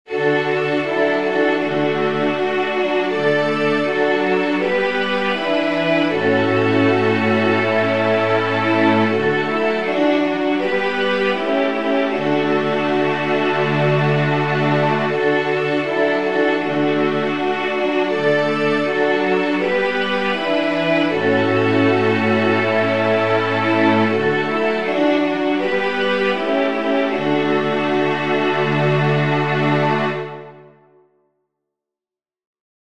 Chants de Kyrie Eleison.